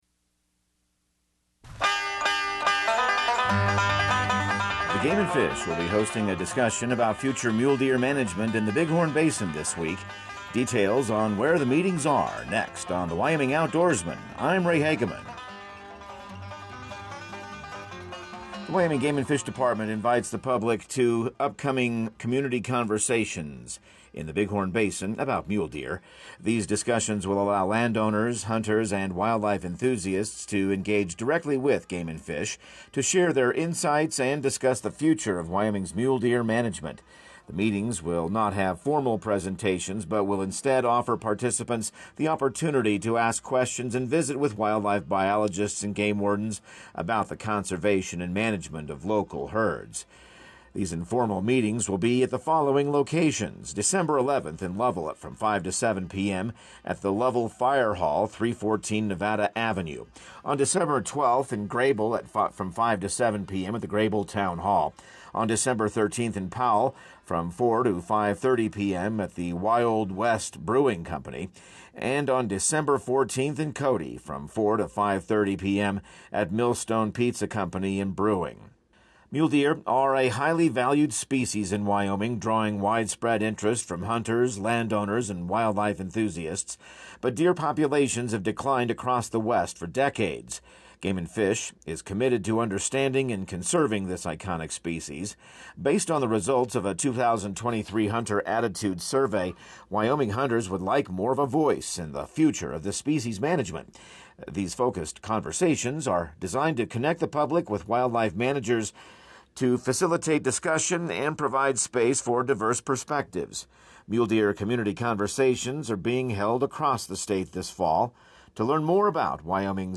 Radio news | Week of December 9